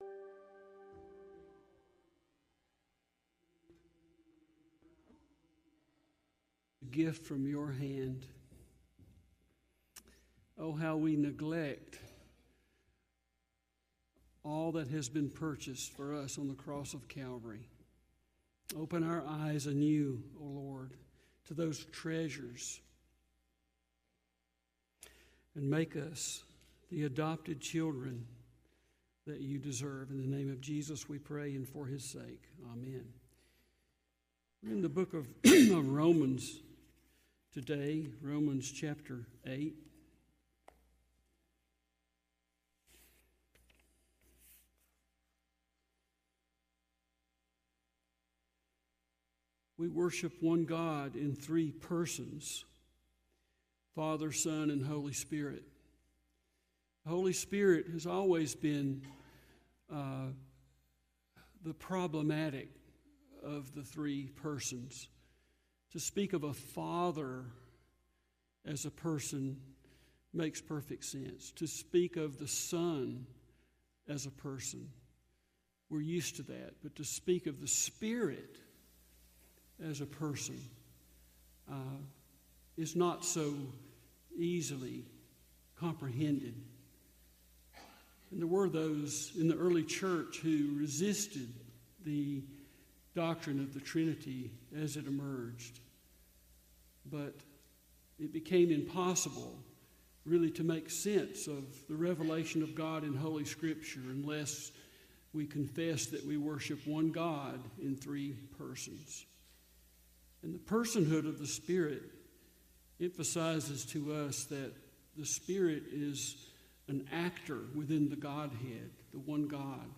Sermons | Smoke Rise Baptist Church